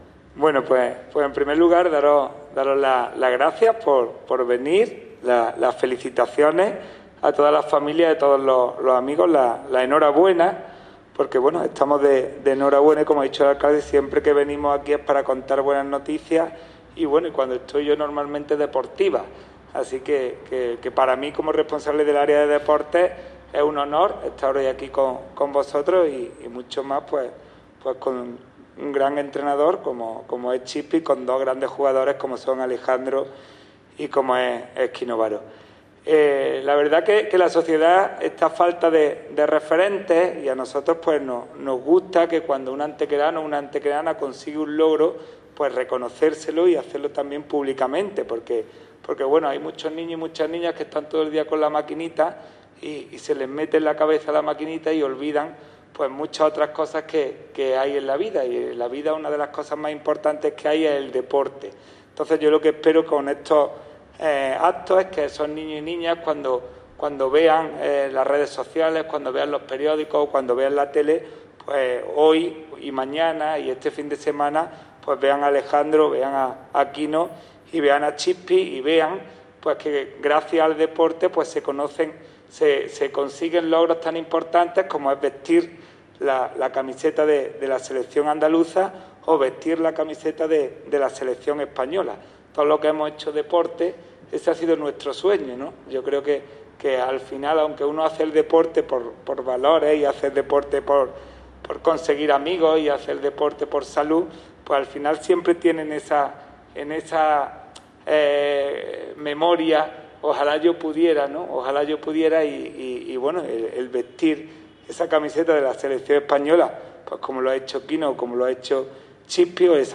El alcalde de Antequera, Manolo Barón, y el teniente de alcalde delegado de Deportes, Juan Rosas, han presidido en la tarde de este martes una recepción en el Salón de Plenos a jugadores y entrenadores de balonmano en nuestra ciudad que han cosechado recientemente éxitos destacados en competiciones nacionales e internacionales.
Cortes de voz